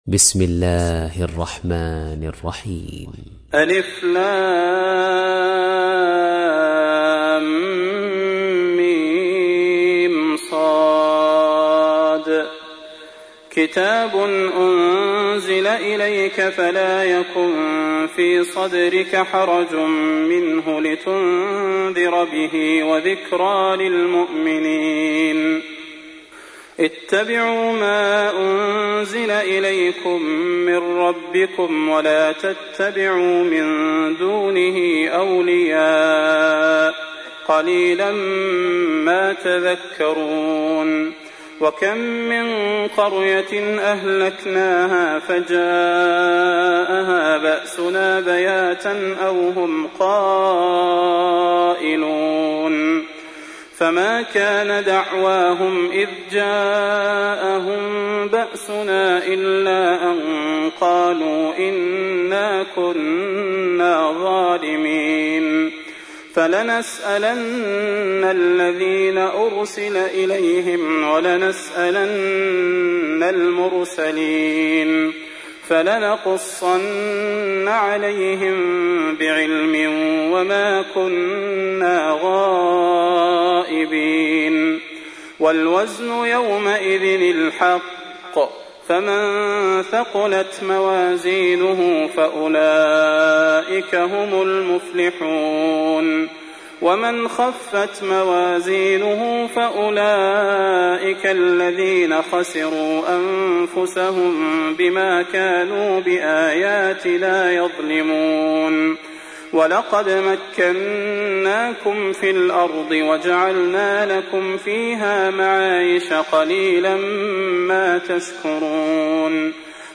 تحميل : 7. سورة الأعراف / القارئ صلاح البدير / القرآن الكريم / موقع يا حسين